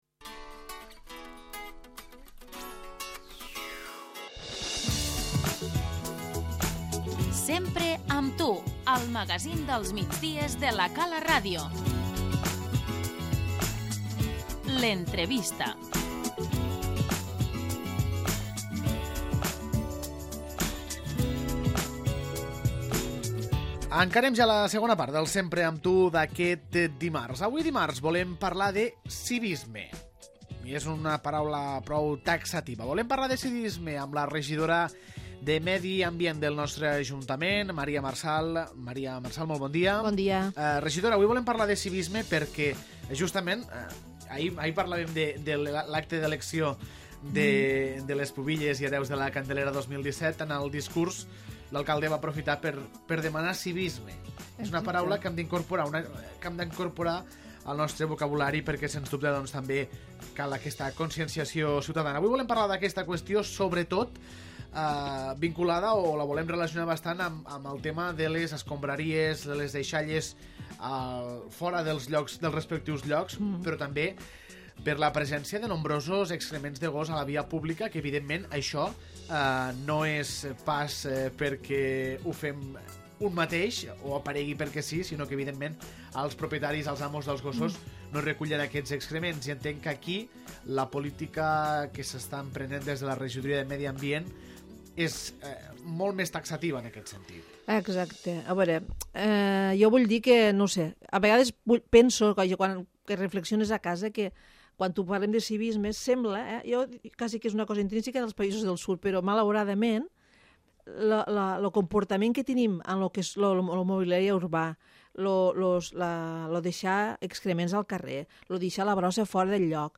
L'entrevista - Maria Marsal, regidora de Medi Ambient